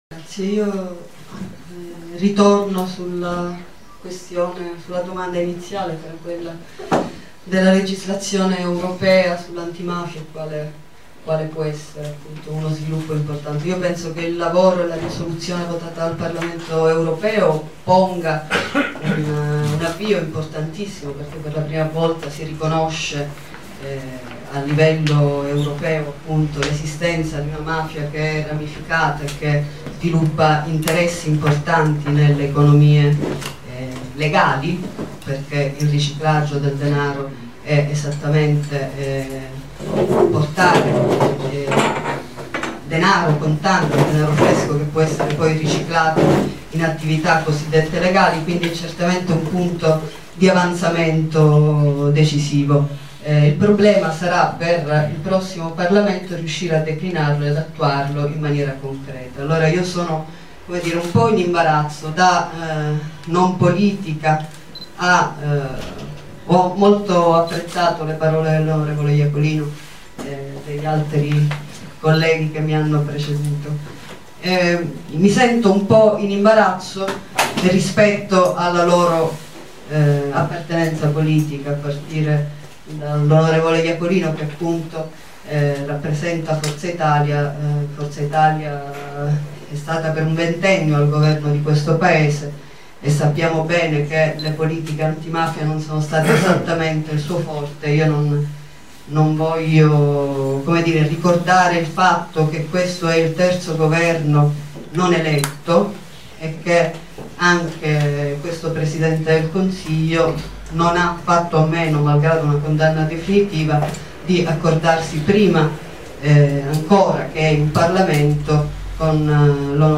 FORUM CON I CANDIDATI ALLE ELEZIONI EUROPEE 2014
CENTRO STUDI PIO LA TORRE
PALERMO